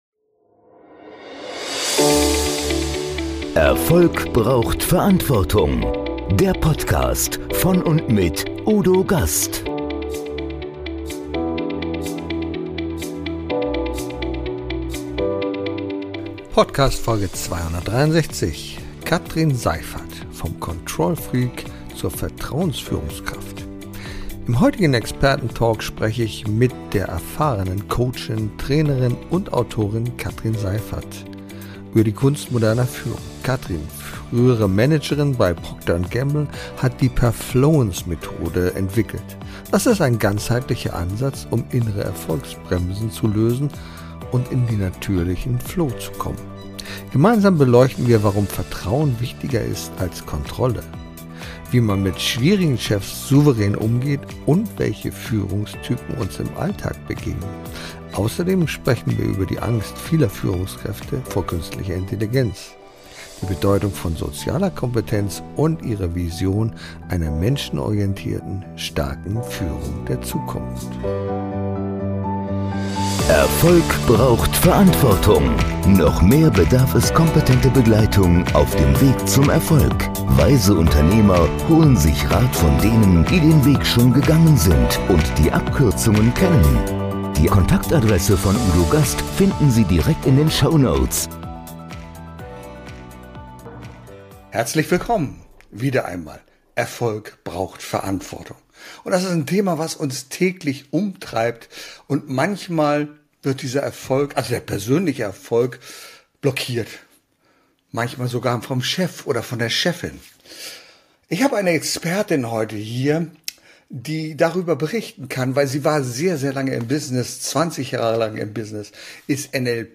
Beschreibung vor 5 Monaten Im heutigen Experten Talk spreche ich mit mit der erfahrenen Coachin, Trainerin und Autorin